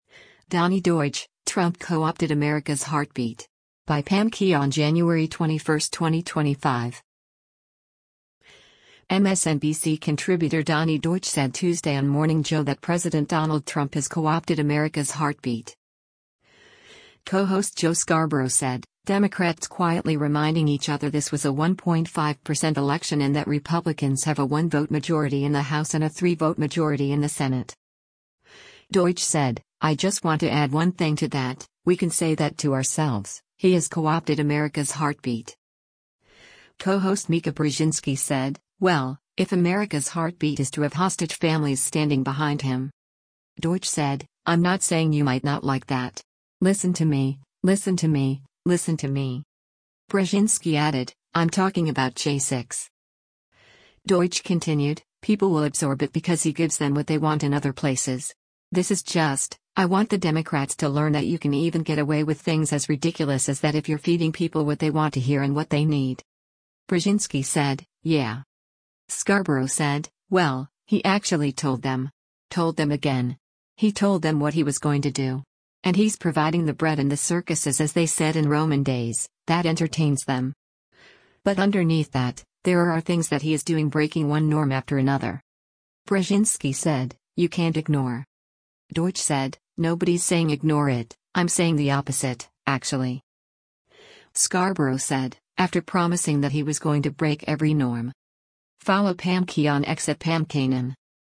MSNBC contributor Donny Deutsch said Tuesday on “Morning Joe” that President Donald Trump “has co-opted America’s heartbeat.”